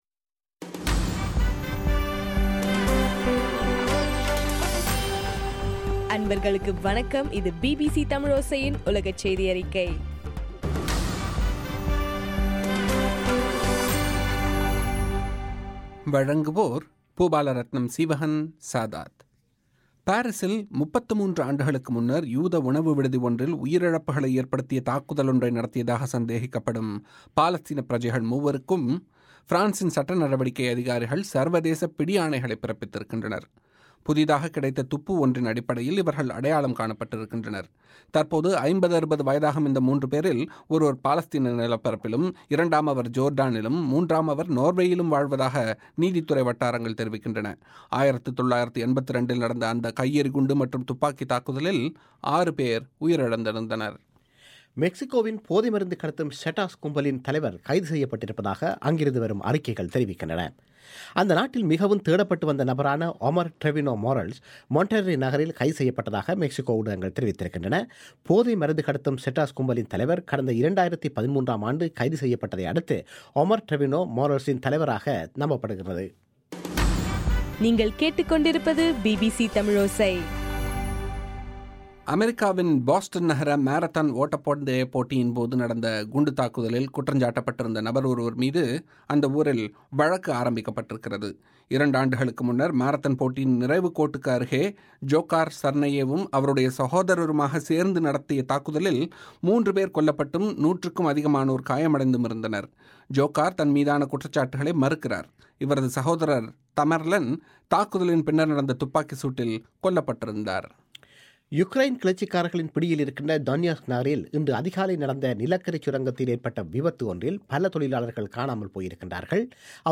மார்ச் 4 2015 பிபிசி தமிழோசையின் உலகச் செய்திகள்